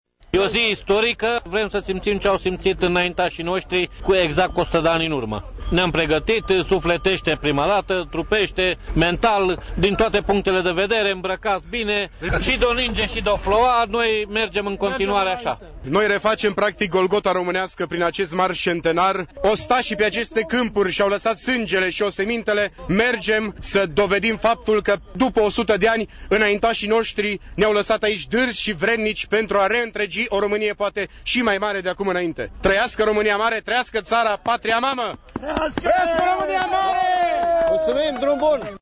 Caravana, cu cinci căruțe, tixite de români, a trecut duminică, 25 noiembrie şi prin judeţul Braşov, respectiv prin comuna Feldioara, venind de la Vâlcele:
Vox-mars.mp3